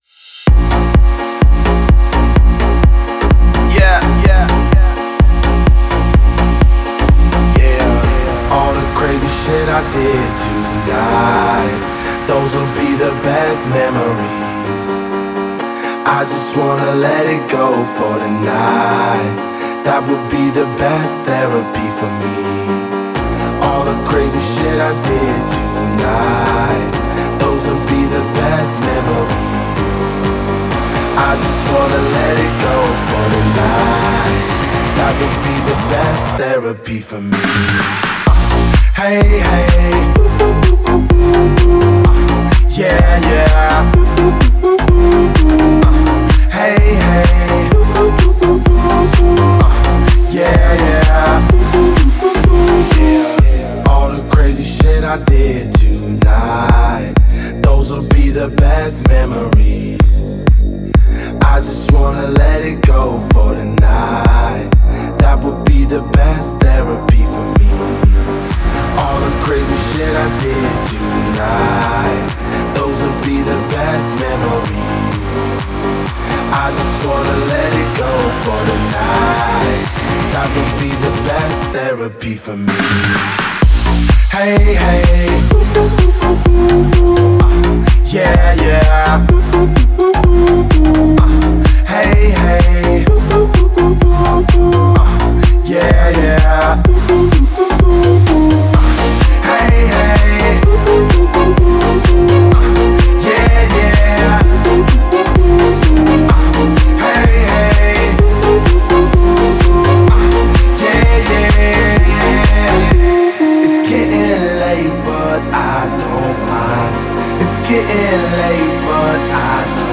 это динамичная танцевальная композиция в жанре EDM